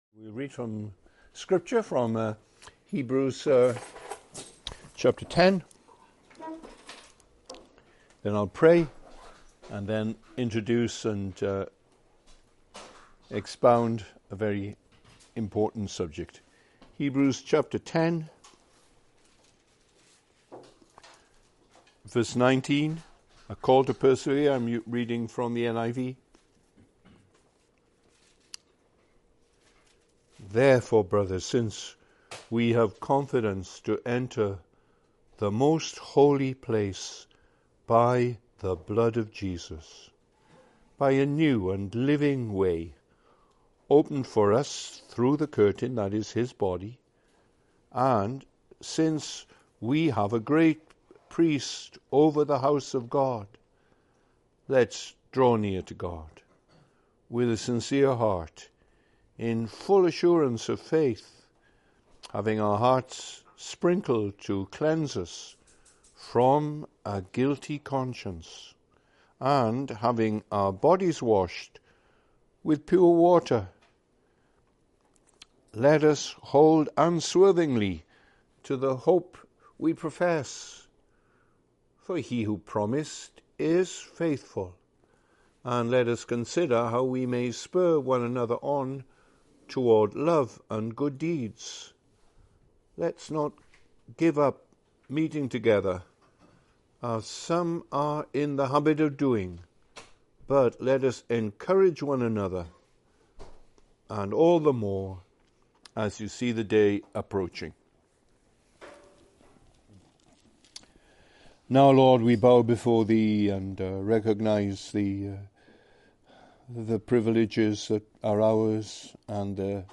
Category: Bible Studies